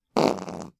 人类" 放屁2
描述：一个屁
标签： 风能 嘟嘟 flatulate 肠胃气胀 气体
声道立体声